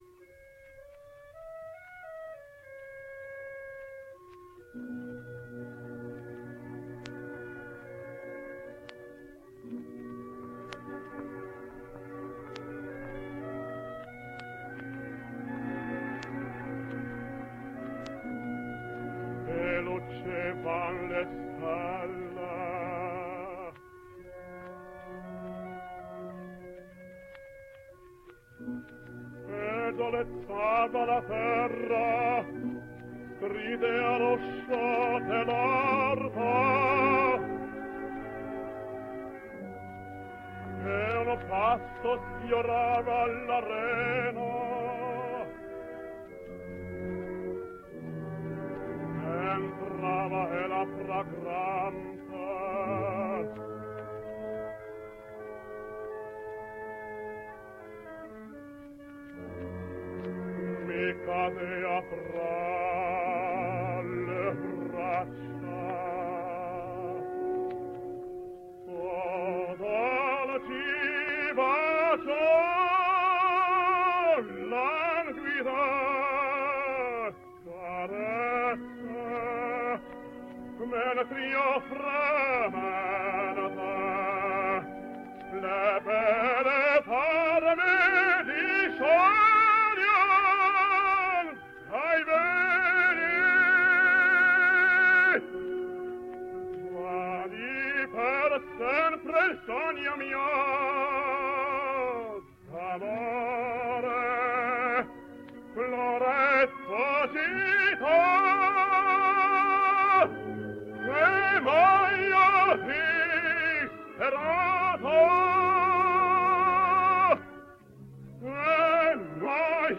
Italian Tenor
His voice is soft and gracious, smooth in the lyric passages and of a glowing intensity in the dramatic.